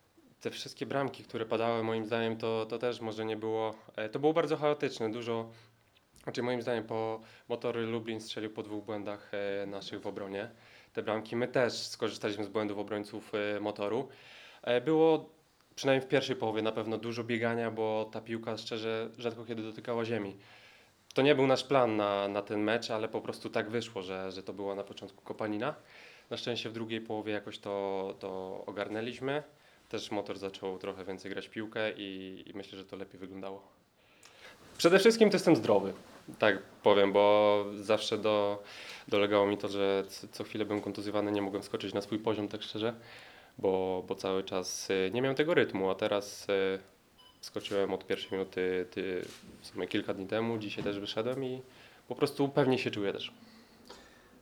Suwałki – Stadion Miejski